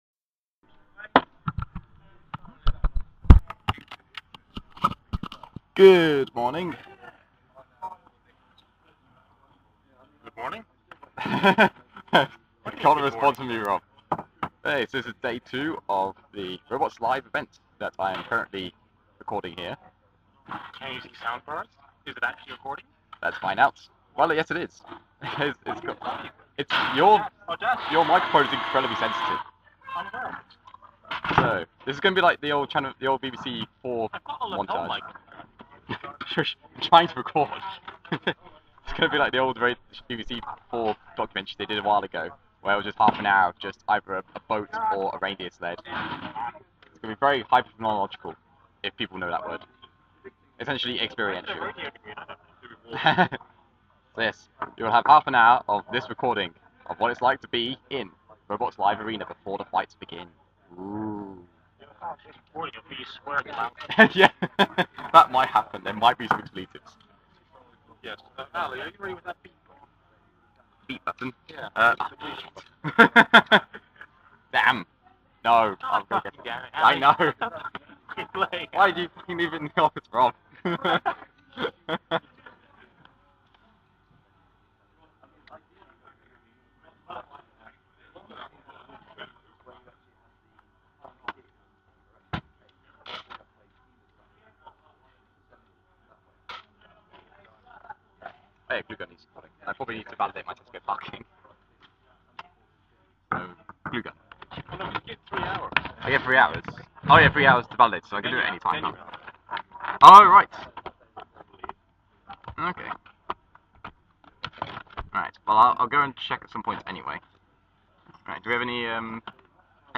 Here’s something to listen to in the background of any important project- the pre-fight tinkering of robots on event day. This was on set at Robots Live in Stevenage, before the UK Championships 2017. Unedited bliss.
NB NOT FOR CHILDREN as it does contain swears.
Alongside this there are high-pitched and high-volume machines, hammers banging on things, testing robots in the arena before the show etc. Not recommended at high volume as that Dremel (which is used frequently early on) really is like having a dentist’s drill inside your mouth (although I could have stood a bit further away from the mike)!!